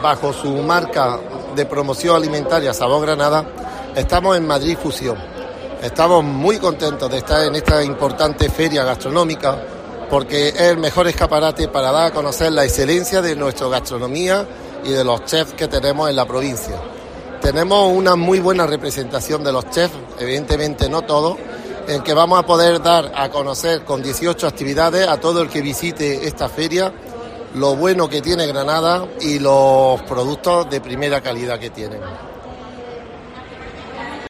Antonio Díaz, diputado de Fondos Europeos, Desarrollo, Industria y Empleo